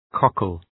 Προφορά
{‘kɒkəl}